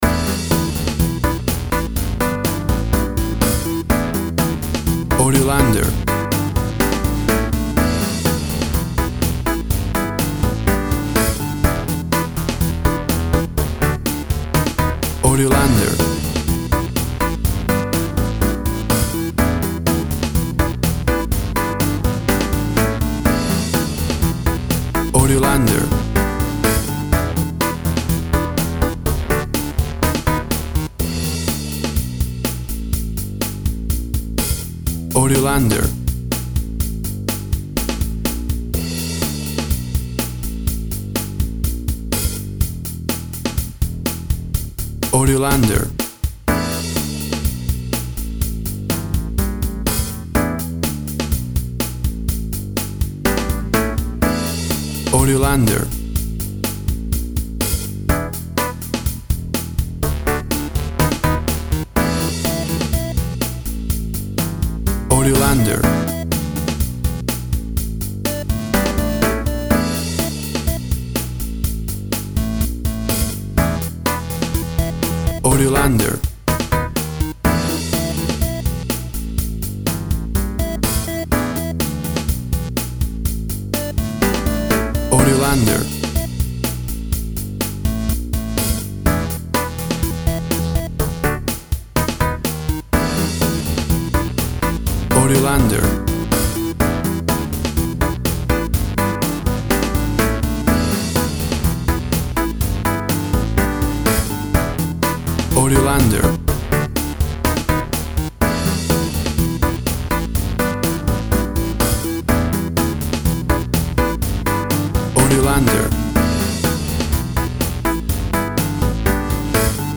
Tempo (BPM) 125